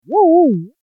Wouwou - ウォウ ウォウ
En l'occurence, mon but était de modéliser à partir de fonctions simples (des gaussiennes) un réel échantillon de parole plutôt simple dirons-nous: "a-o-i", puis de synthétiser le résultat de mon modèle pour savoir s'il valait quelque chose. Comme vous vous en rendrez compte en cliquant ici ou sur l'image (en langage technique: un spectre de puissance), on n'obtient pas toujours des résultats très satisfaisants, mais ils ont au moins le mérite d'être rigolos.
今回は「あおい」という言葉の発話を簡単な関数でモデル化しようとして、その結果を評価するため得られたモデルを合成してみた。